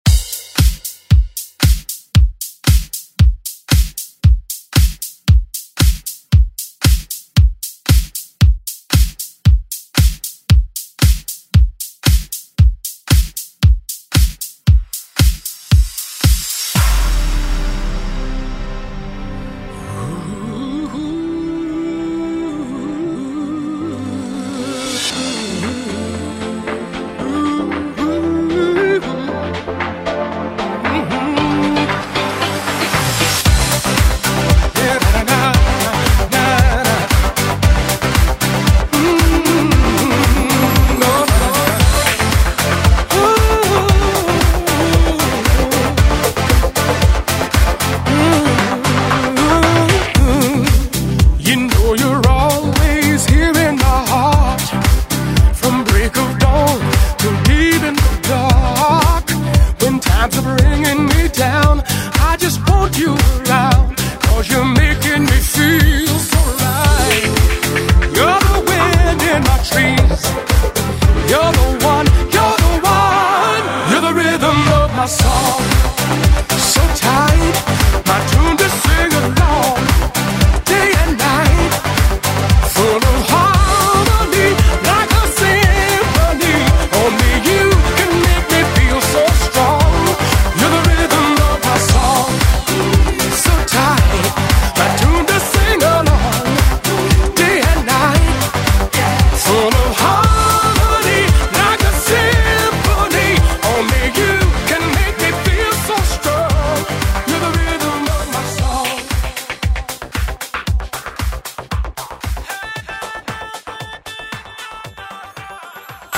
Electronic Euro House Music Extended ReDrum Clean 137 bpm
Genre: 90's
Clean BPM: 137 Time